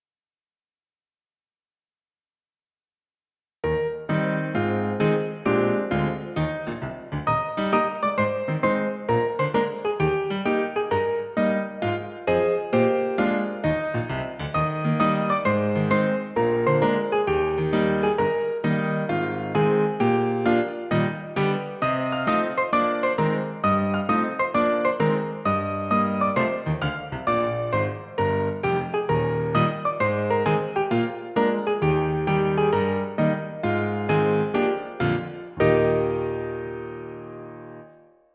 Piano accompaniment
Musical Period 19th century British, Australian, American
Tempo 132
Rhythm March
Meter 4/4